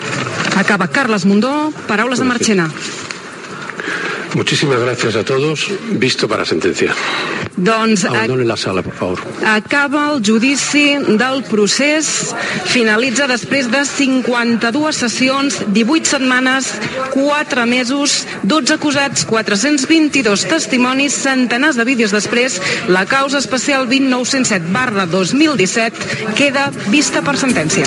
Tancament de la transmissió de l'últim dia del Judici als líders del procés independentista català, fet a Madrid, a la seu del Tribunal Suprem d'Espanya
Informatiu
FM